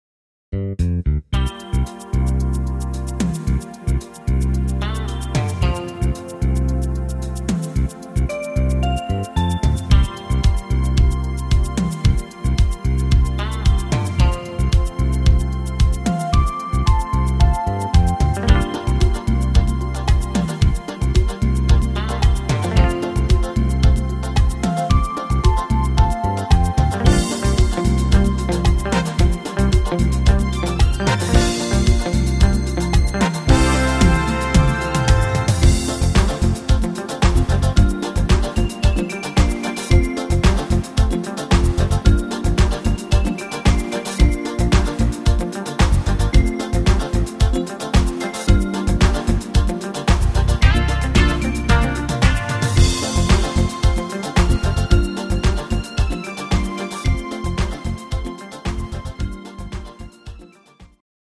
instr. Querflöte
Rhythmus  Disco Funk
Art  Instrumental Allerlei